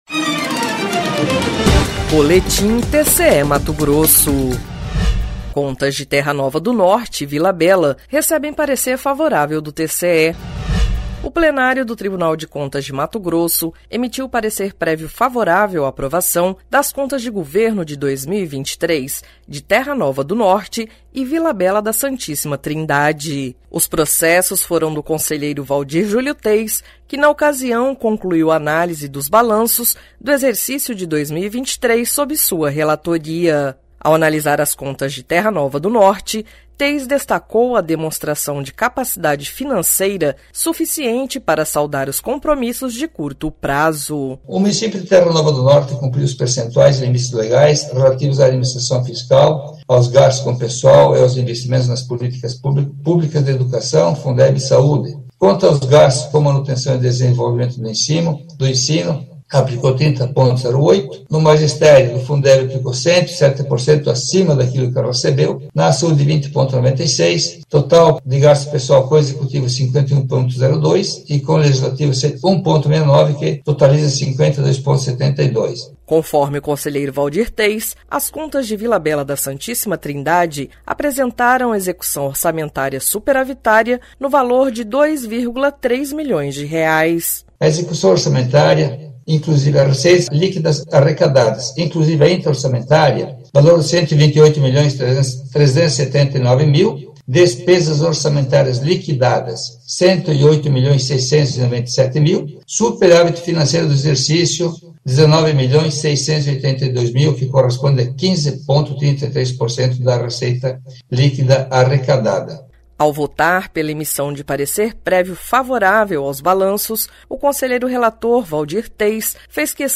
Sonora: Waldir Júlio Teis – conselheiro do TCE-MT
Sonora: Sérgio Ricardo – conselheiro-presidente do TCE-MT